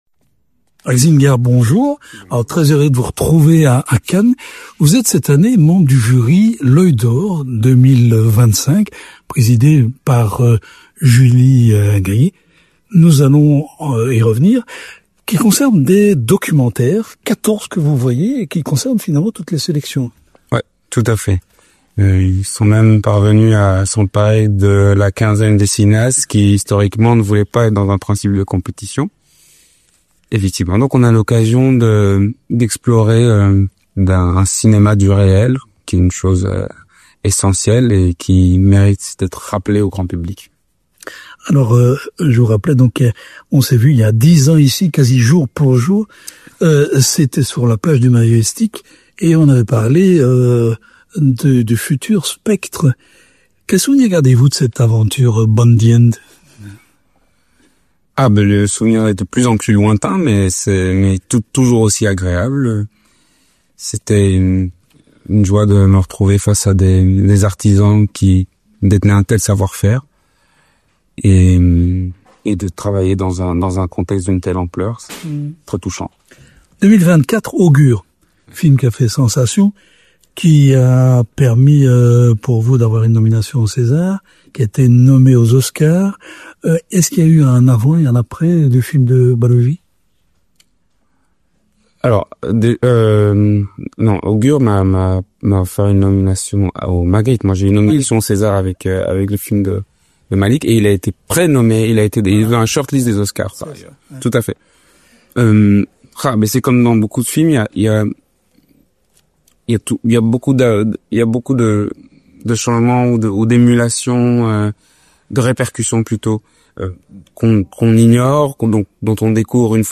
Marc est de retour à Cannes en tant que membre du jury « L’ŒIL D’OR » sous la présidence de Julie Gayet. Rencontre.